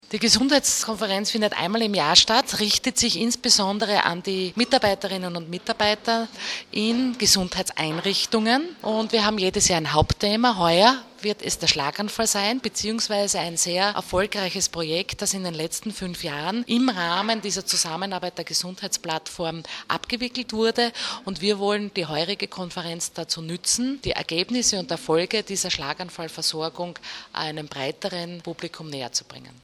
Pressekonferenz zum Thema integrierte Versorgung von Schlaganfallpatienten